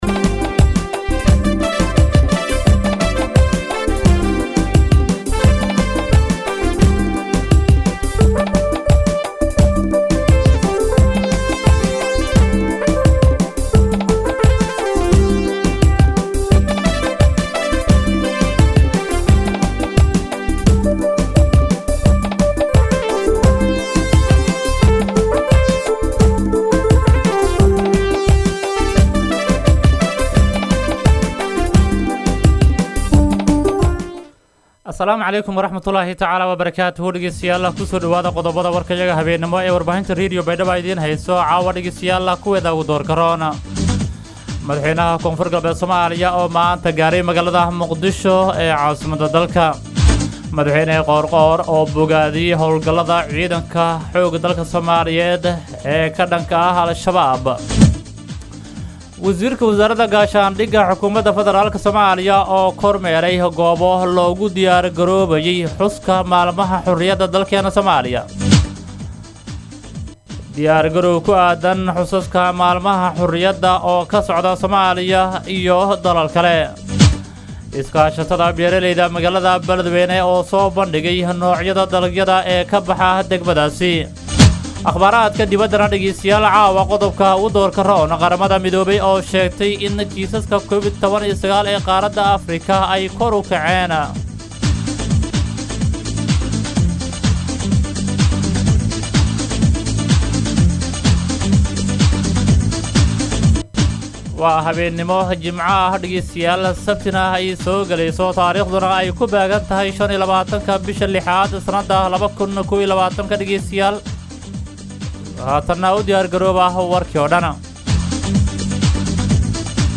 BAYDHABO–BMC:–Dhageystayaasha Radio Baidoa ee ku xiran Website-ka Idaacada Waxaan halkaan ugu soo gudbineynaa Warka maanta ee ka baxay Radio Baidoa.